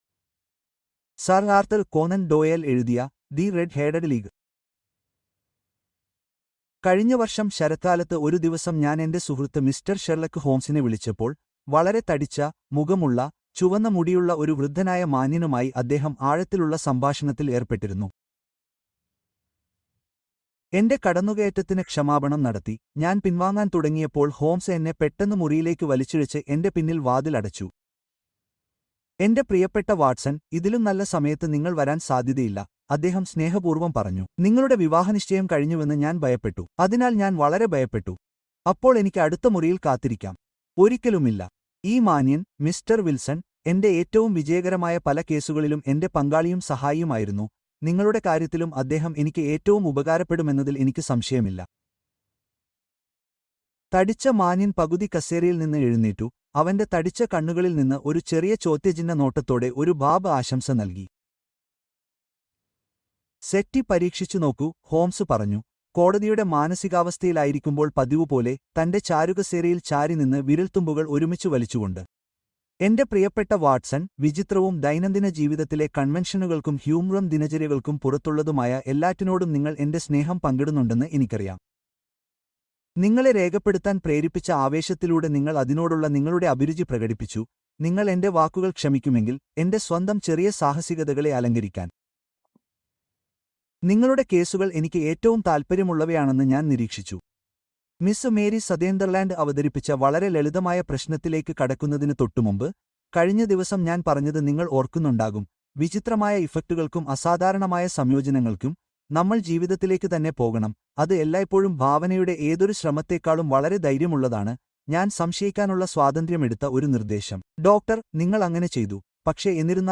The Lord of Chateau Noir: Dark Secrets and Mystery (Audiobook)